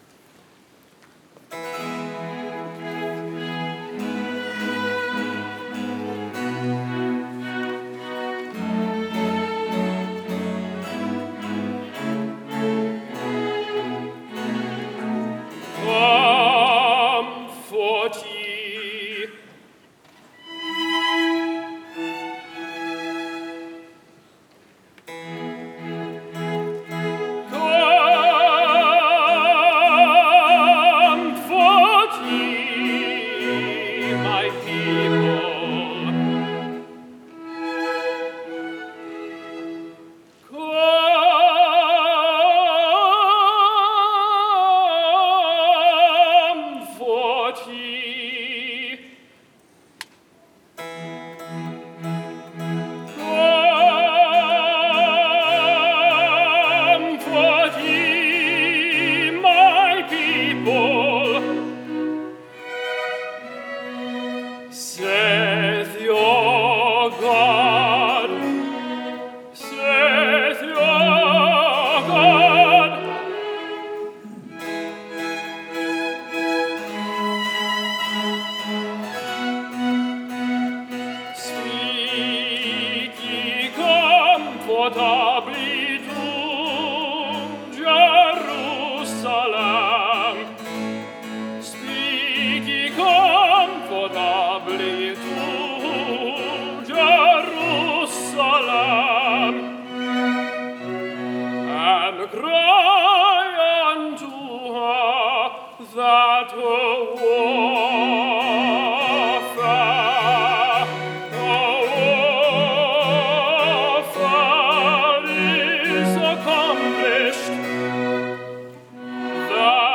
December 13, 2015 Concert
Tenor